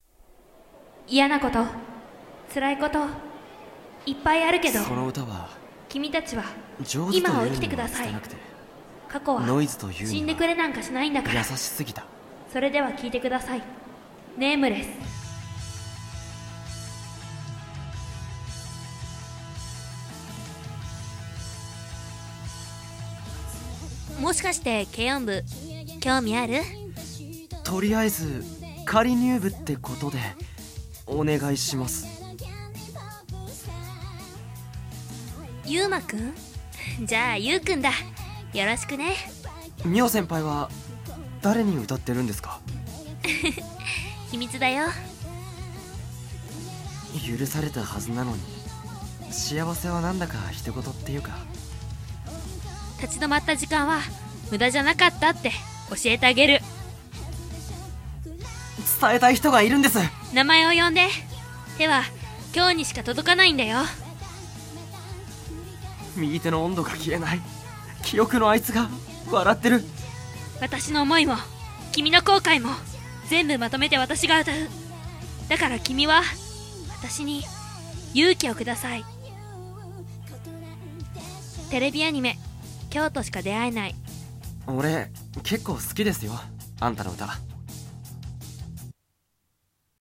【アニメCM風声劇】今日としか出会えない